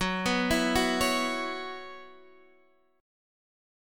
F#7sus4 chord